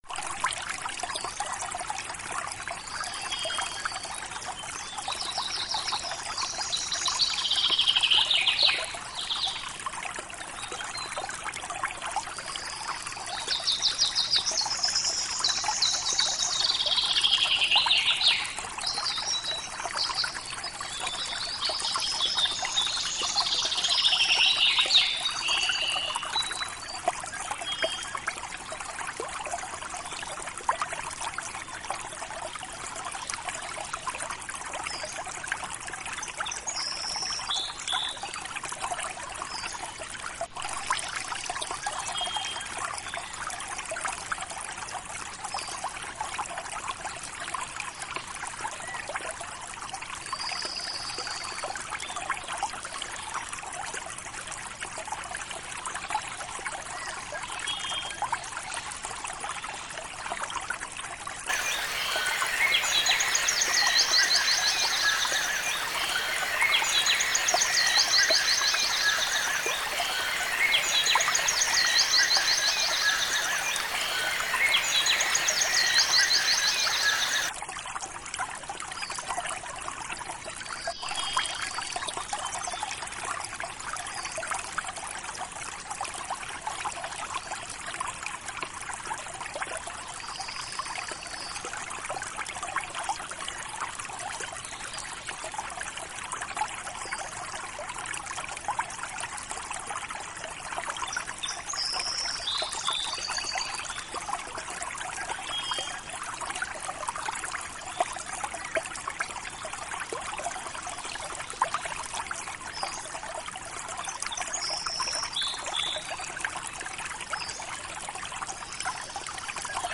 Música para relajarse, pensar y meditar.